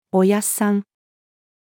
おやっさん-female.mp3